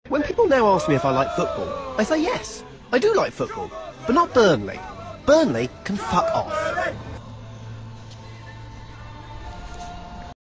Race Cars Ay, Who'd Have Sound Effects Free Download